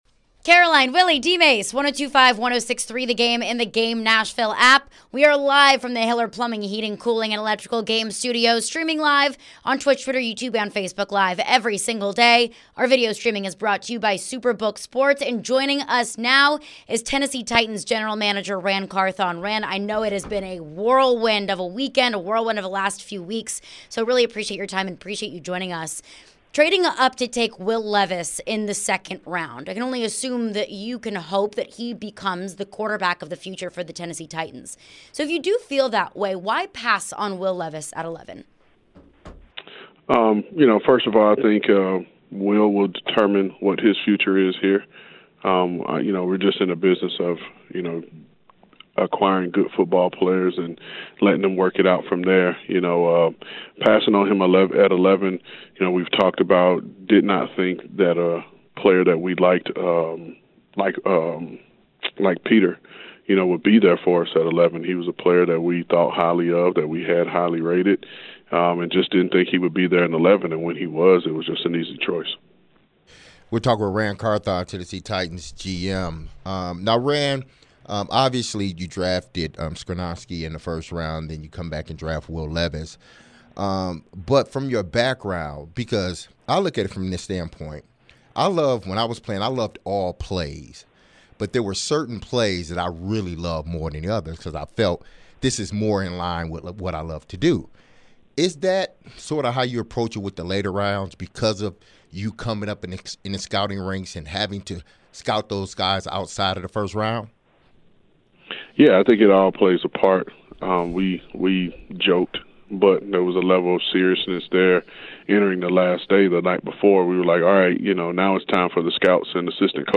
GM Ran Carthon Interview (5-1-23)